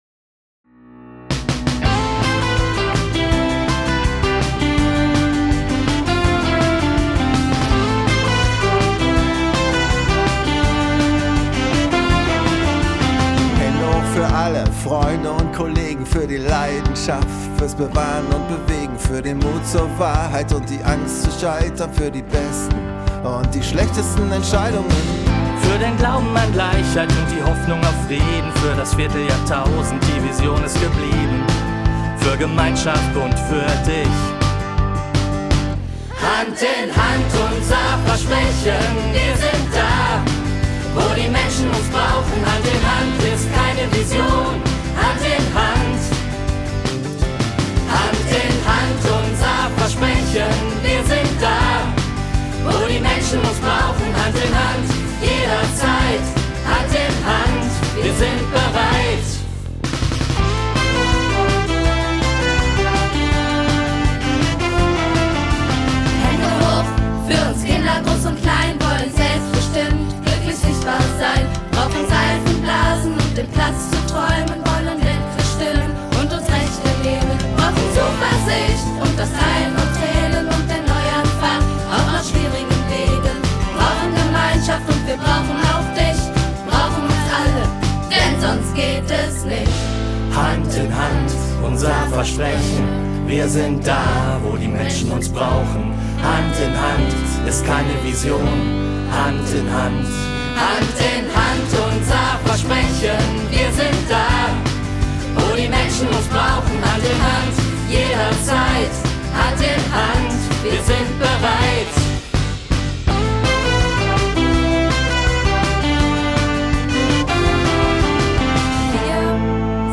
Die Botschaft war schnell gefunden: „Hand in Hand“. Gemeinsam mit Kindern und Mitarbeitenden der Jugendhilfe Iserlohn/Hagen wurde das Lied entwickelt und nach seiner Fertigstellung professionell im Tonstudio aufgenommen. Für die meisten Sänger:innen und Musiker:innen war dies eine ganz neue und besondere Erfahrung.
Trompete
Waldhorn
Saxophon